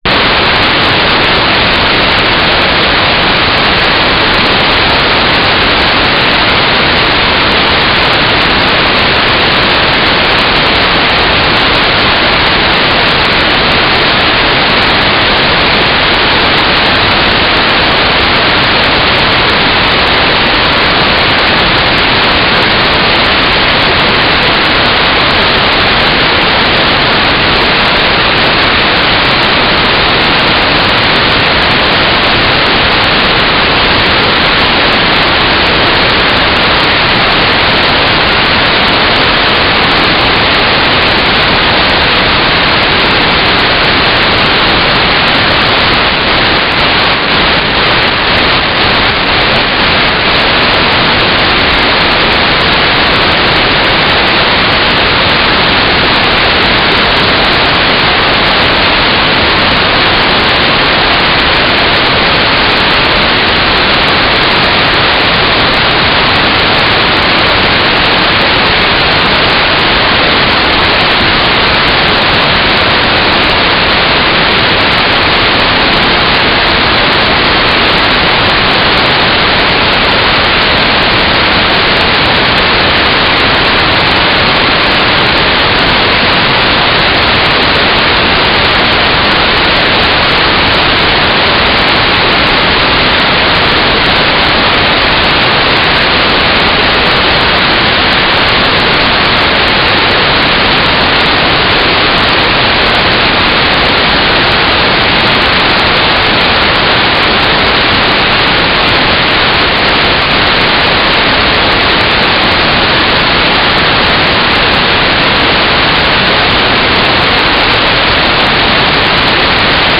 "transmitter_description": "Mode U - GFSK4k8 - AX.25 - Telemetry",